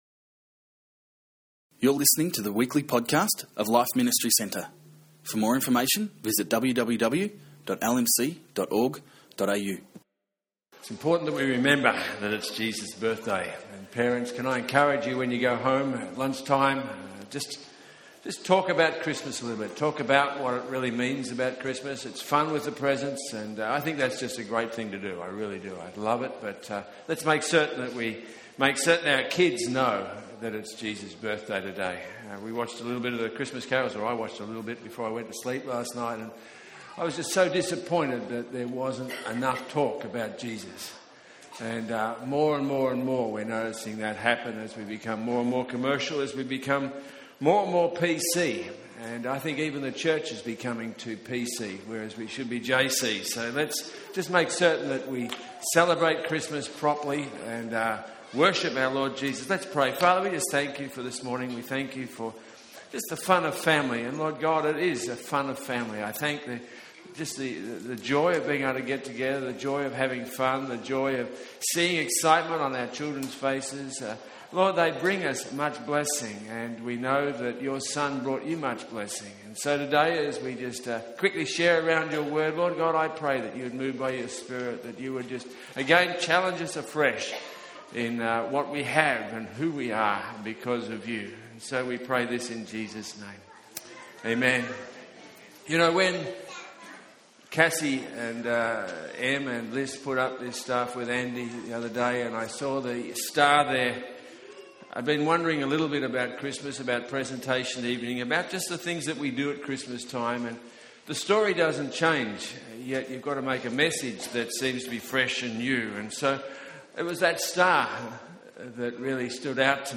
Christmas Message from our service on Christmas Day, 2013.